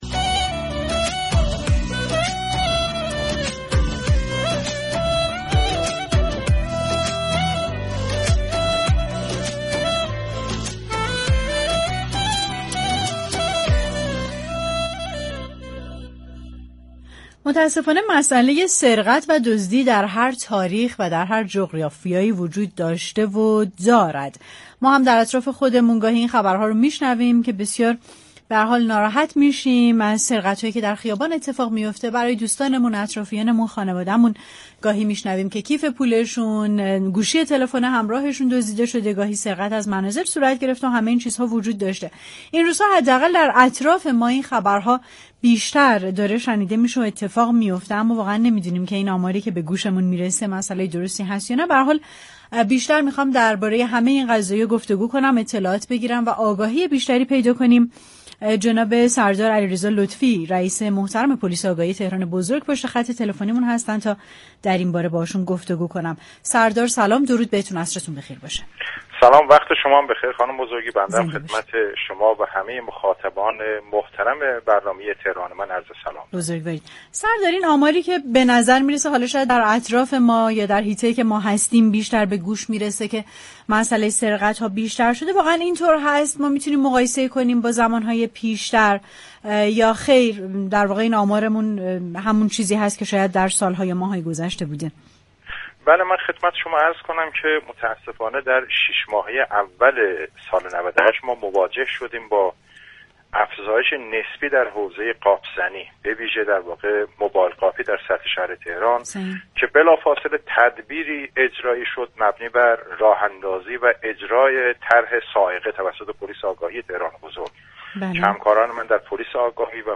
سردار علیرضا لطفی در گفتگو با تهران‌من افزود: بلافاصله طرحی توسط پلیس آگاهی تهران موبایل با عنوان "صاعقه" تدوین و اجرایی شد كه بر مبنای این طرح گروه‌هایی برای مقابله با كیف‌قاپی و موبایل قاپی سازمان دهی شد و در مدت كوتاهی آمار سرقت‌های یاد شده كاهش یافت.